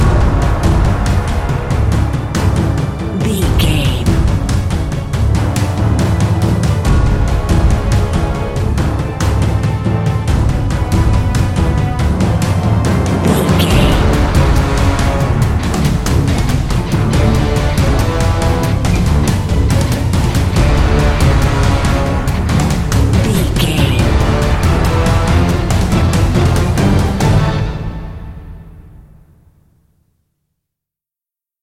Fast paced
In-crescendo
Aeolian/Minor
horns
percussion
electric guitar